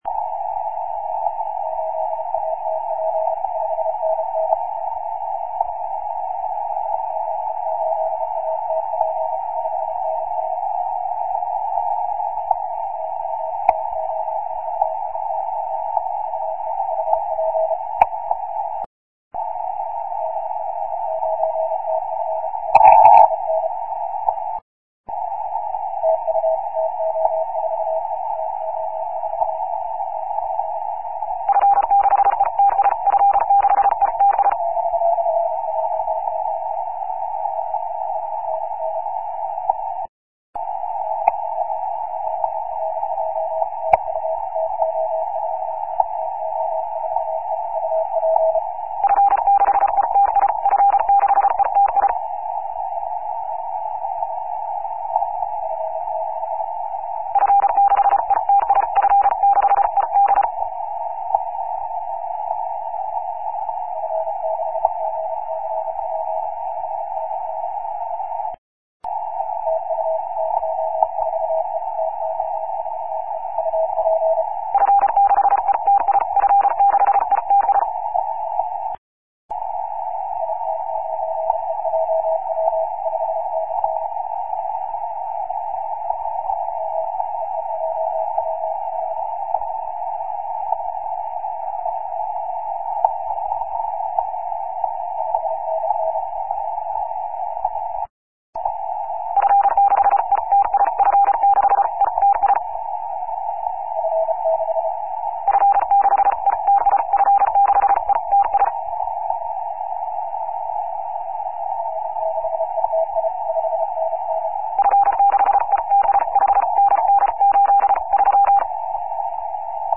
18 MHz CW, 02/12/03